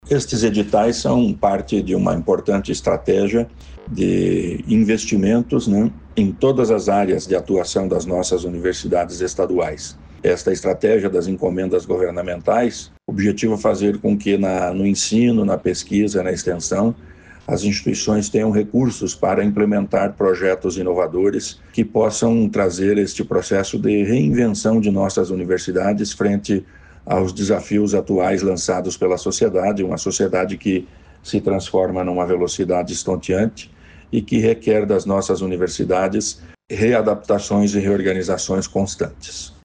Sonora do secretário da Ciência, Tecnologia e Ensino Superior, Aldo Bona, sobre os projetos de ensino e extensão